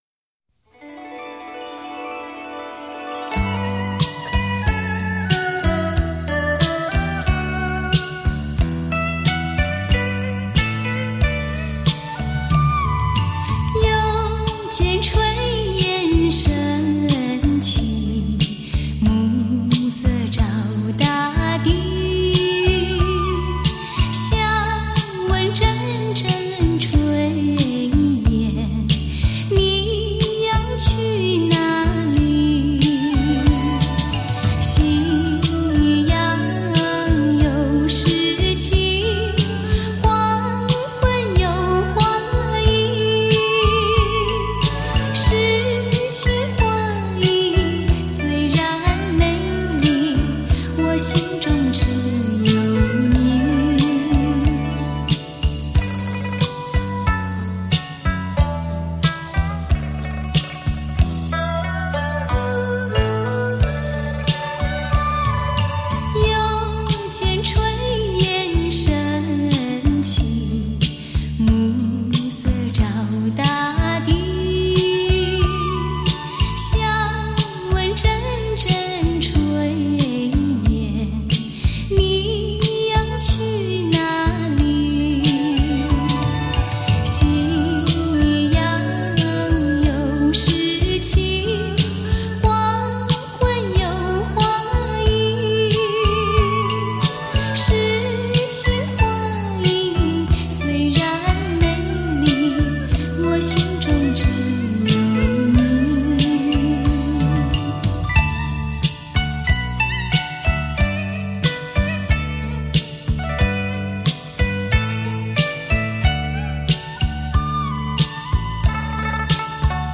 甜而温柔的音符，